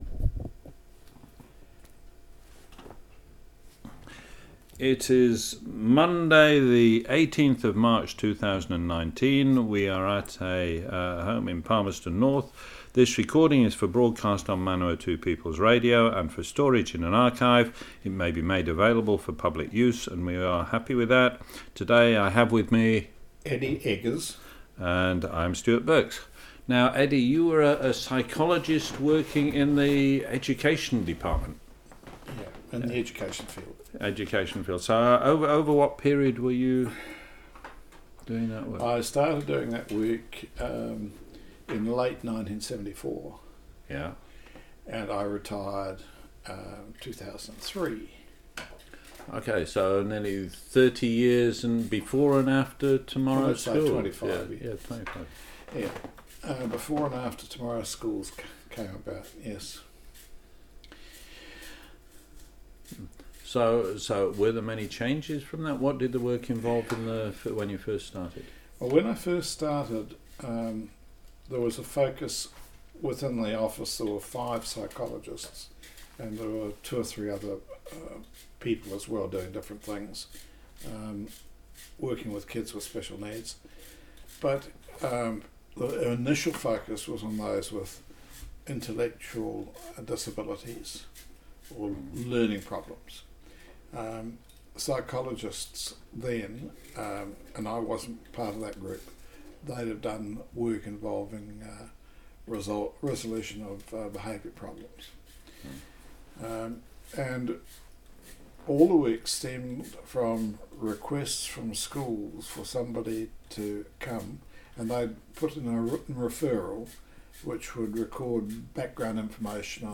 First broadcast on Manawatu People's Radio, 11 June, 2019. Working in the education psychology field 1974 to 2003.